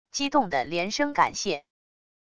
激动的连声感谢wav音频